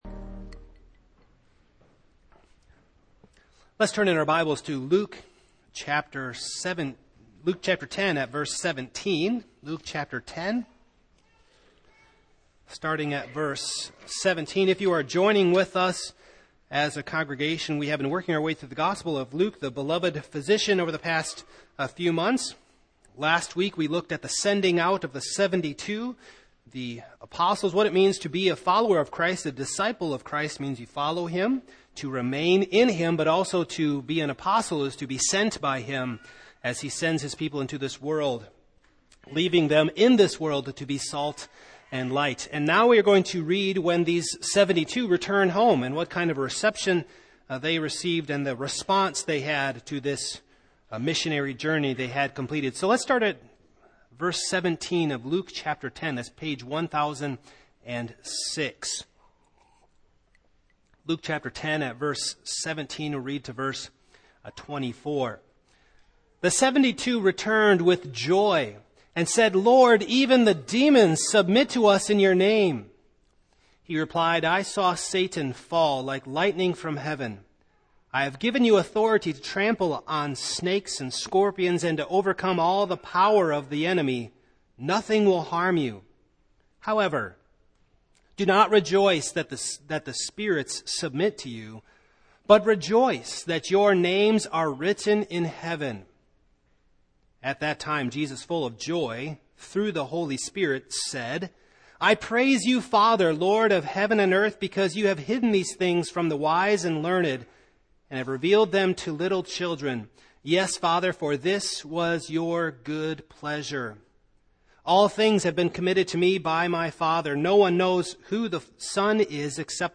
Passage: Luke 10:17-24 Service Type: Morning